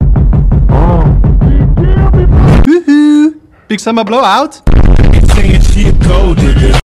This is a cool sound for zooms!